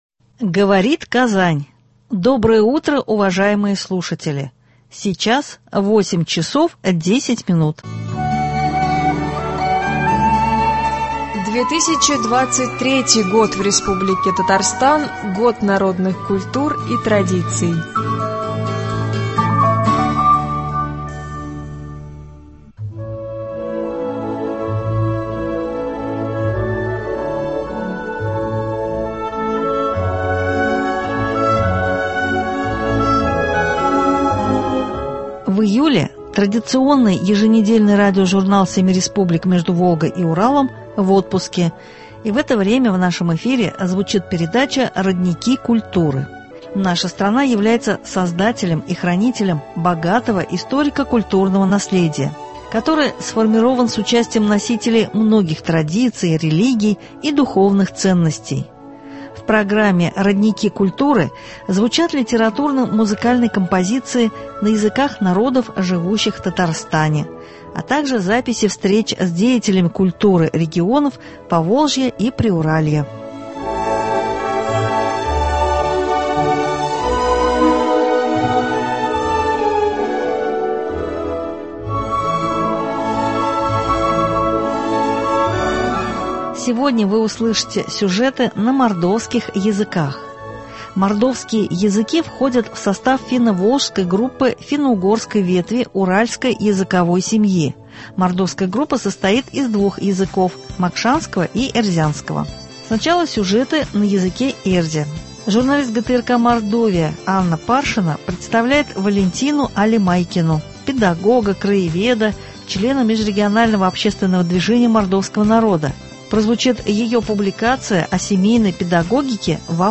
В программе «Родники культуры» звучат литературно — музыкальные композиции на языках народов, живущих в Татарстане, записи встреч с деятелями культуры регионов Поволжья и Приуралья.